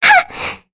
Mirianne-Vox_Attack4.wav